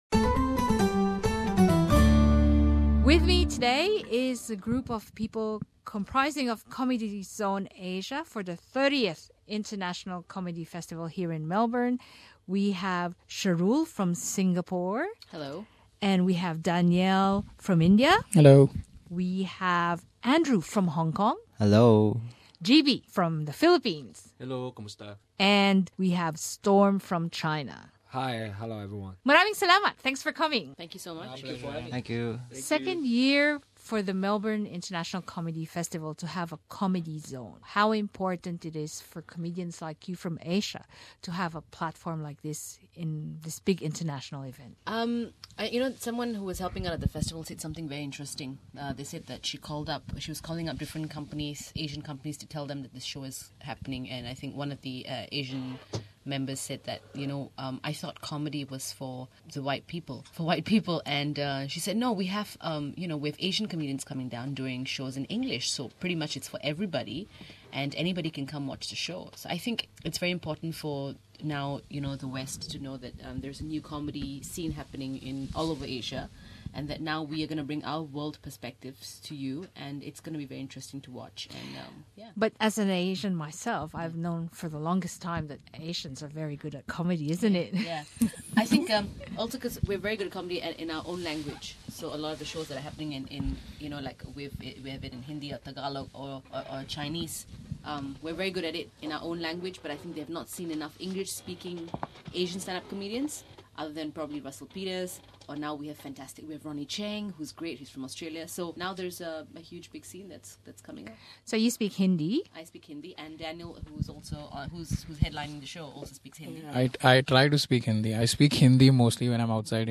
What happens when you guest five comedians?